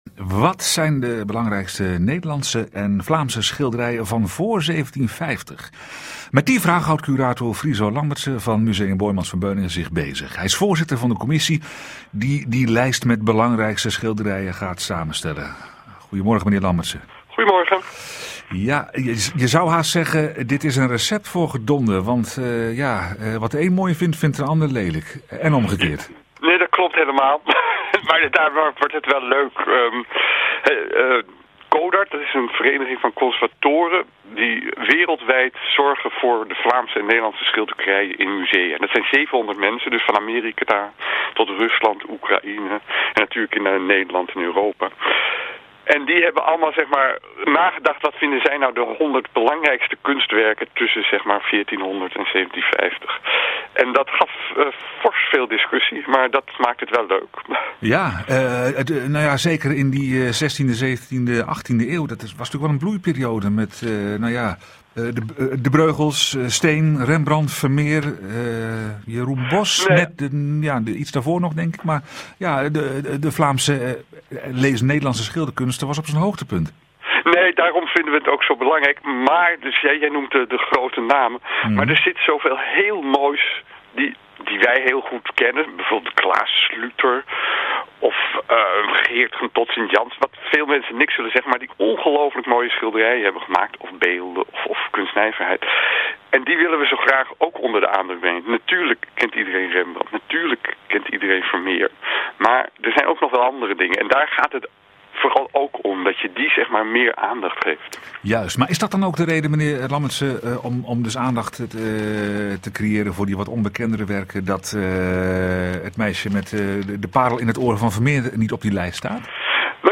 RTV Rijnmond, Moet ‘ De Nachtwacht’ of ‘Het Melkmeisje’ in de top-100 mooiste kunstwerken? , 20 November 2019 including an interview